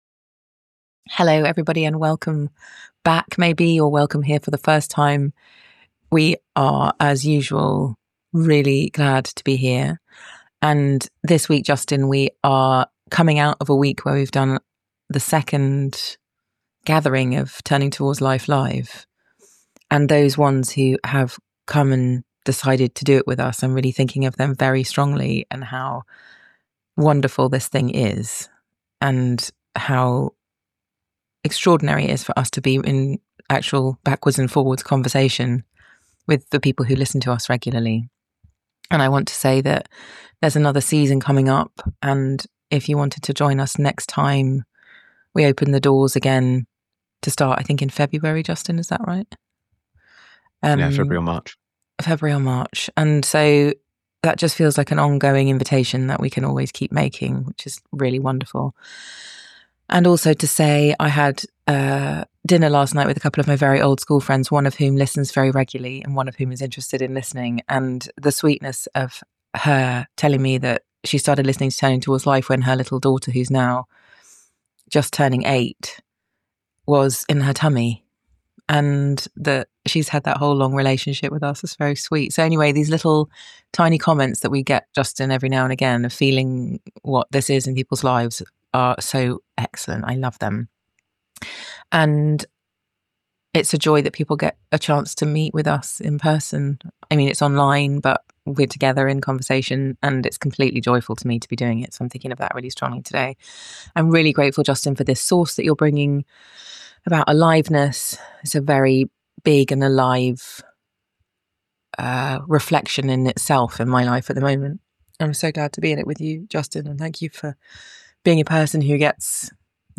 In this conversation we explore what it is to attend to aliveness, how we might come to notice its presence, and consider steps any of us can take to cultivate it.